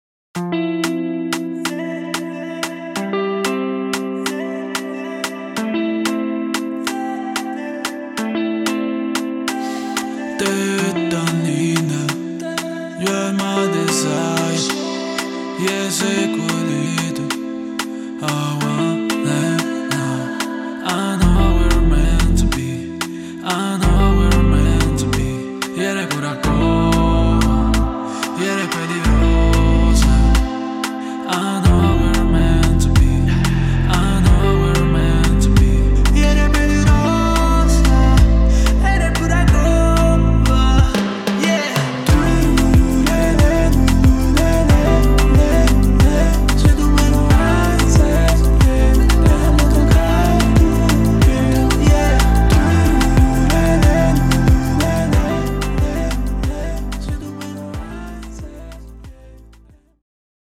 Dancehall
• 27 Drum Loops